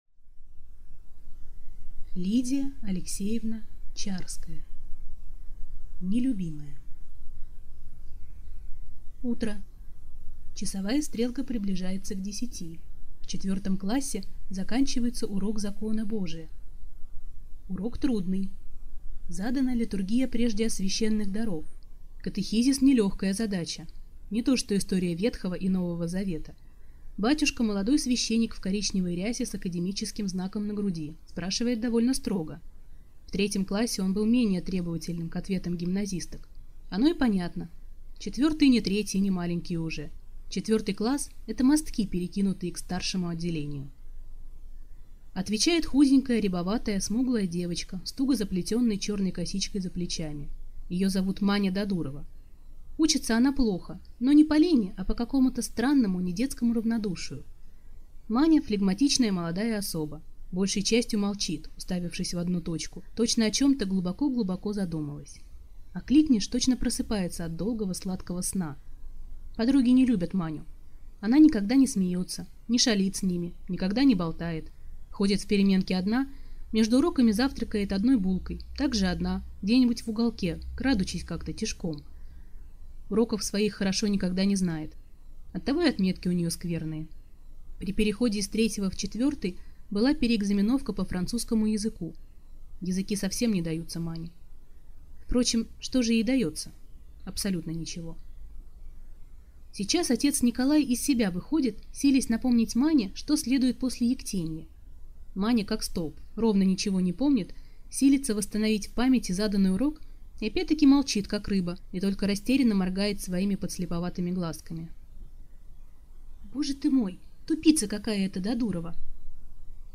Аудиокнига Нелюбимая | Библиотека аудиокниг
Прослушать и бесплатно скачать фрагмент аудиокниги